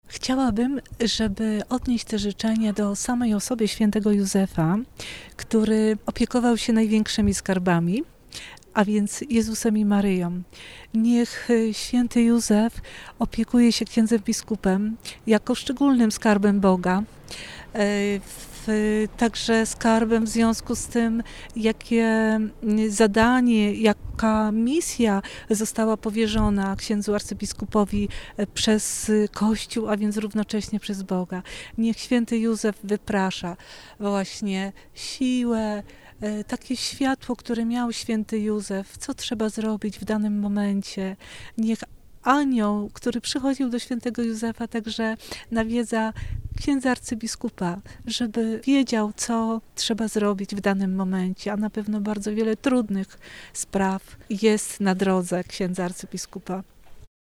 Z tej okazji życzenia Księdzu Arcybiskupowi składają wierni archidiecezji.